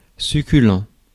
Ääntäminen
IPA : /ˈteɪst.fəl/